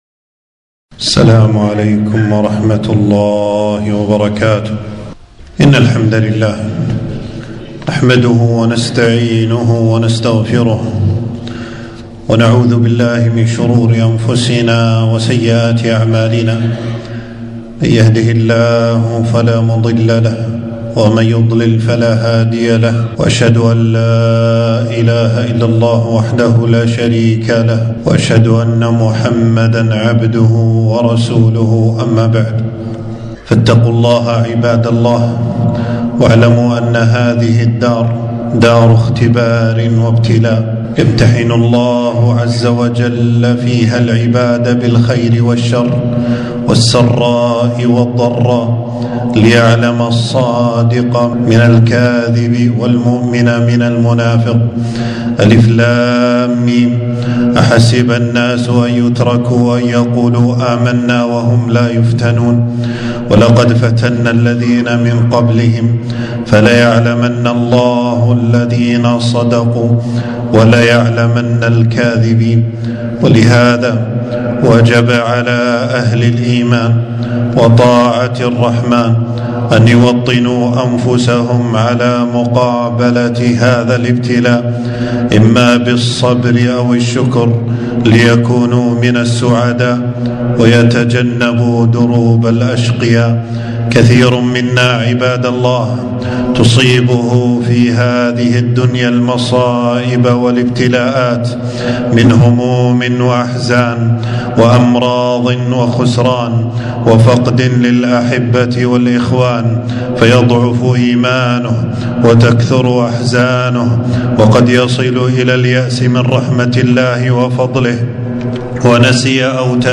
خطبة - التوكل على الله منجاة للعبد